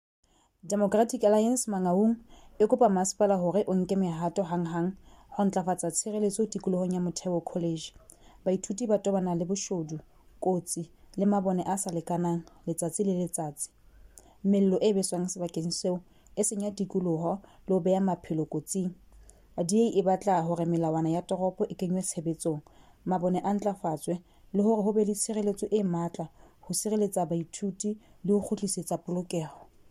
Sesotho soundbites by Cllr Lebohang Mohlamme.